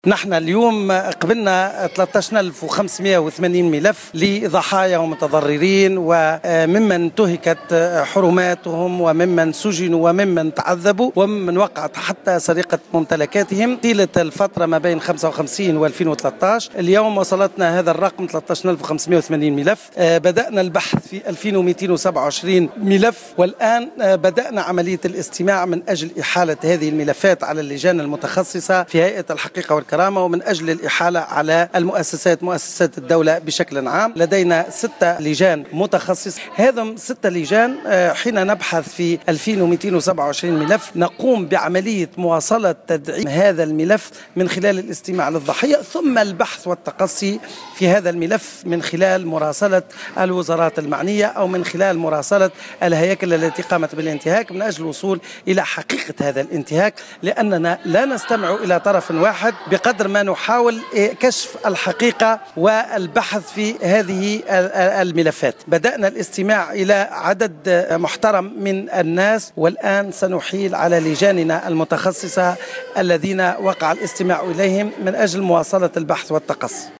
تلقت هيئة الحقيقة و الكرامة الى حد الان ثلاثة عشر الف وخمس مئة وثمانين ملفا وفق ما صرّح به لمراسلة الجوهرة أف أم، نائب رئيس الهيئة زهير مخلوف على هامش ورشة عقدت صباح اليوم الأحد في نابل للتنسيقية الجهوية الاولى من اجل تفعيل مسار العدالة الانتقالية .